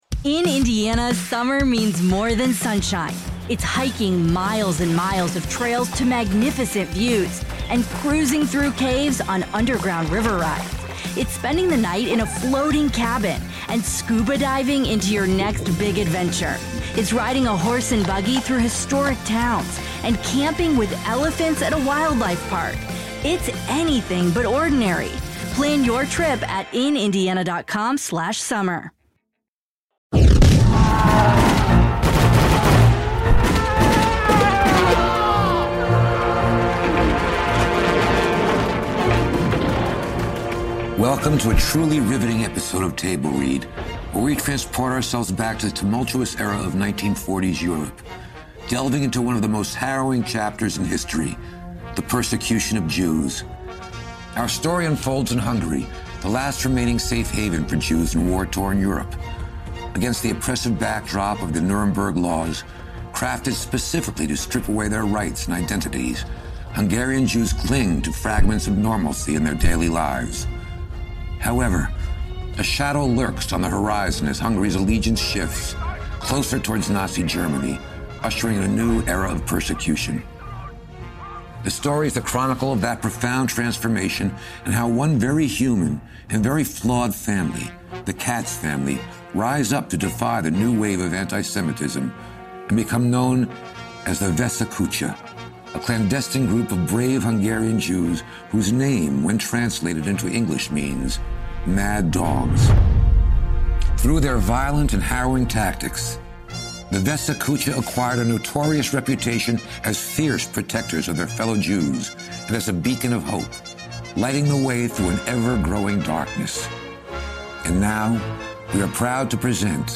Audio Drama Manifest Media Cinema Live Theater Performance Hollywood Music Cinematic Entertainment Storytelling Award Winning Manifest Media / Realm Script Actor
Presented by Nomono and recorded exclusively with Nomono Sound Capsules.